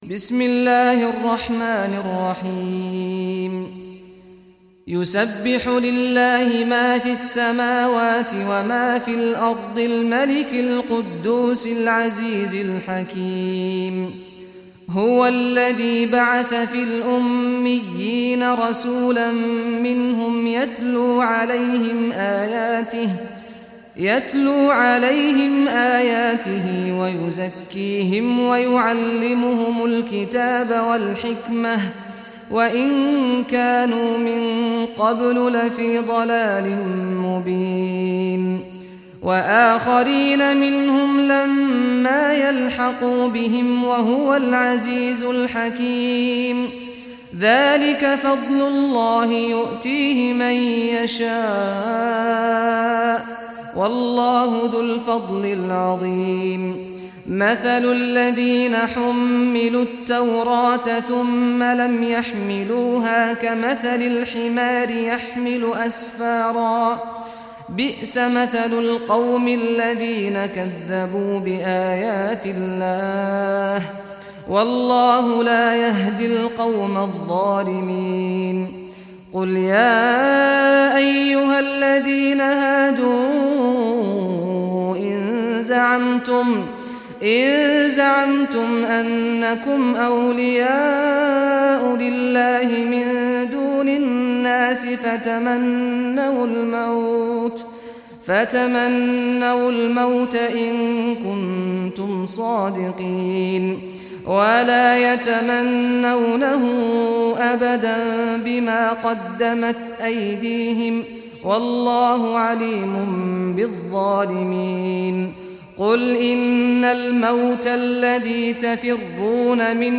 Surah Jumah Recitation by Sheikh Saad al Ghamdi
Surah Jumah, listen or play online mp3 tilawat / recitation in Arabic in the beautiful voice of Sheikh Saad al Ghamdi.